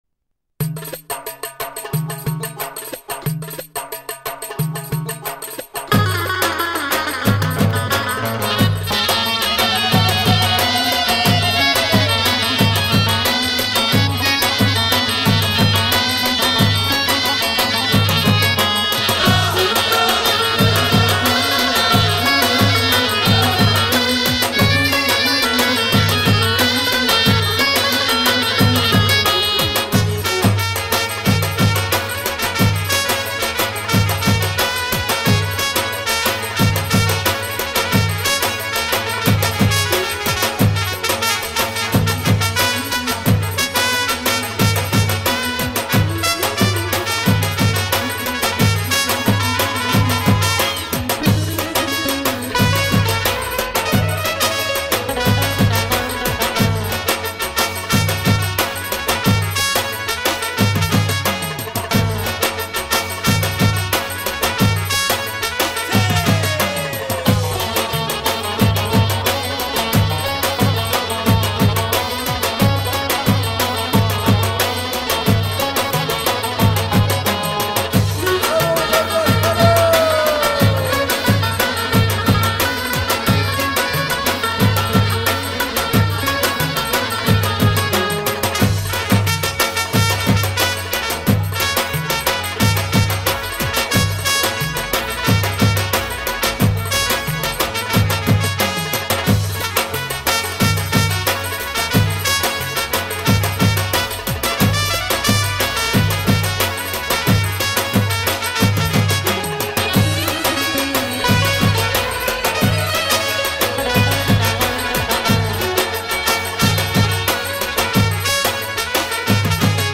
نسخه بی کلام